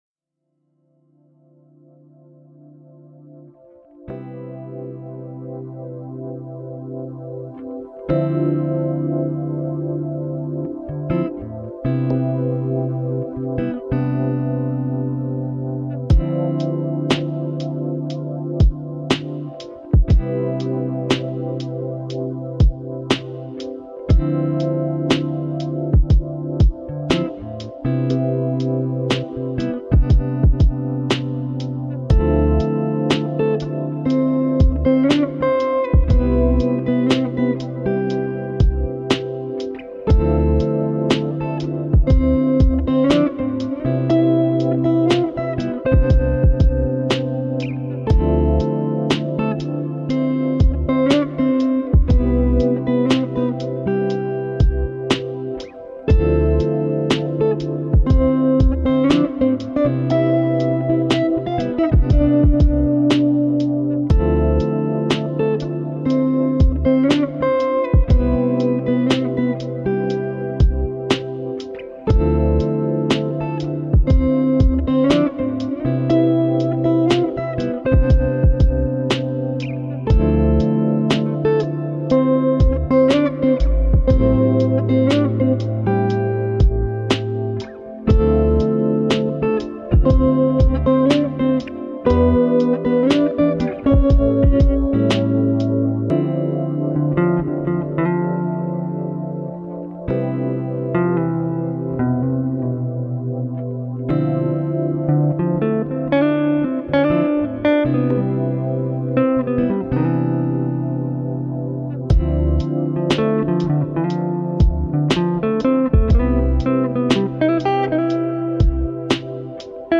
Pluie Nocturne Pour Étude